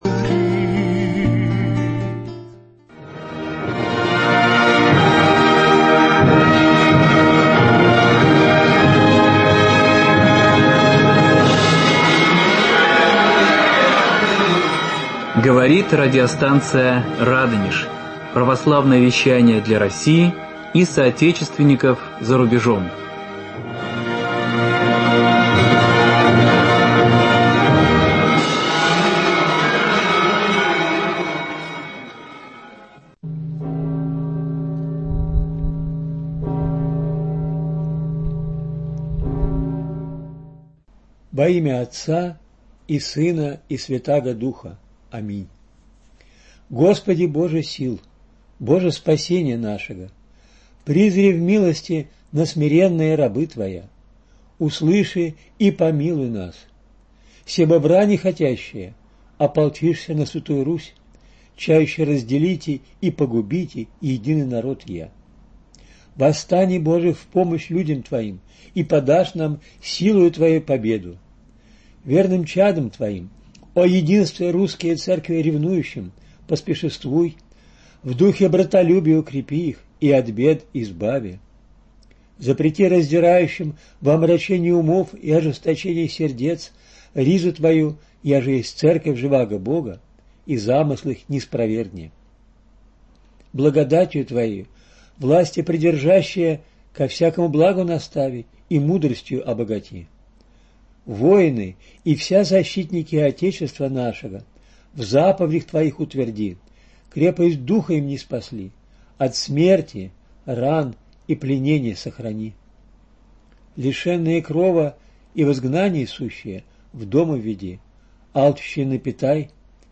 В эфире новая передача из авторского радиоцикла кандидата философских наук